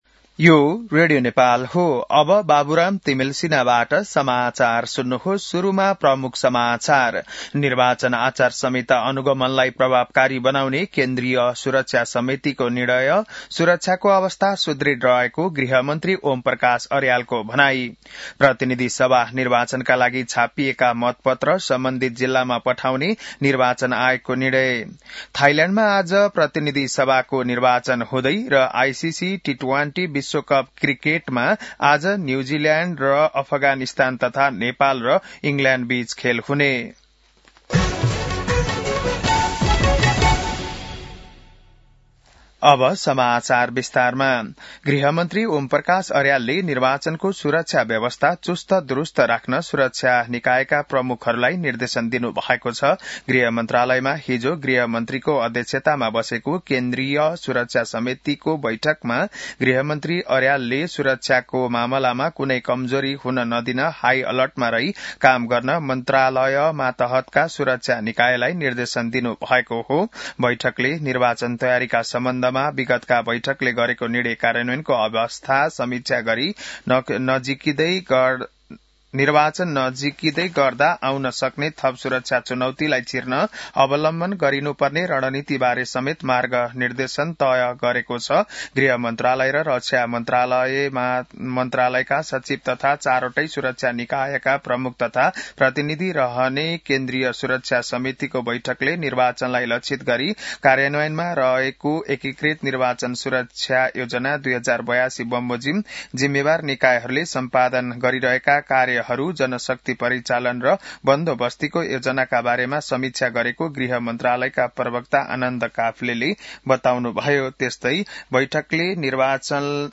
बिहान ९ बजेको नेपाली समाचार : २५ माघ , २०८२